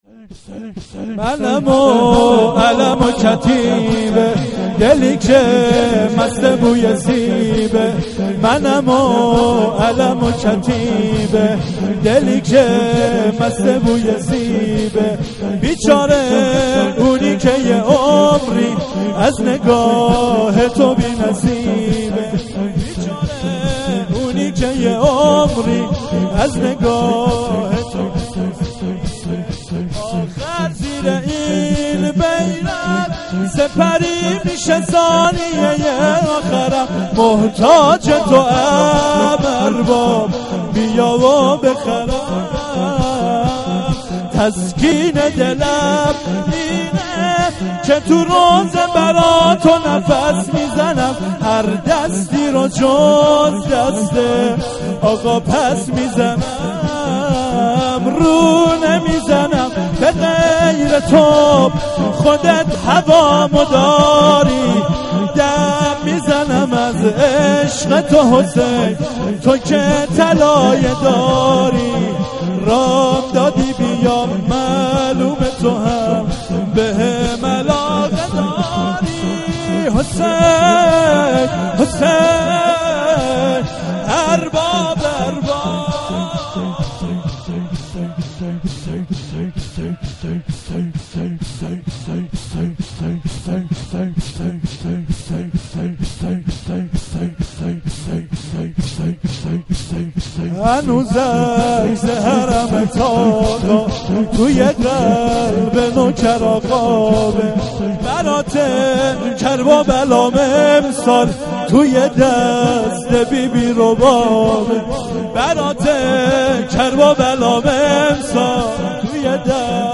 شور2